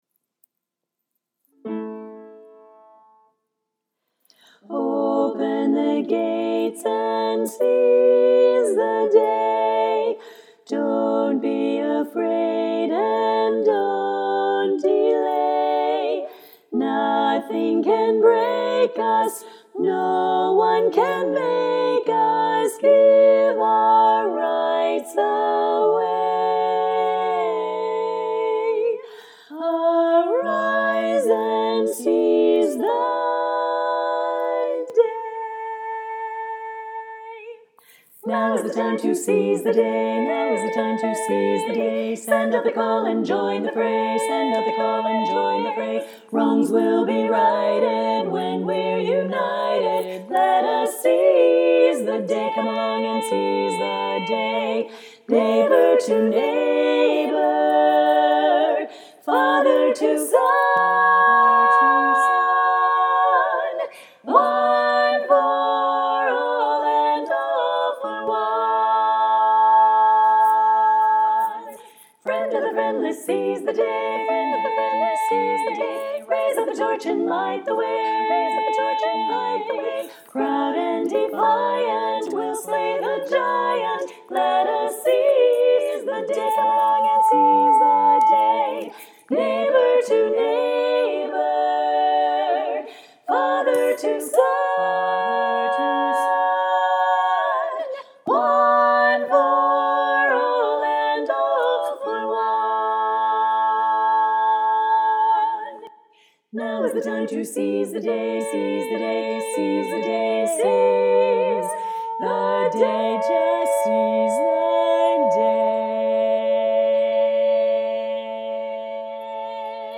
Lead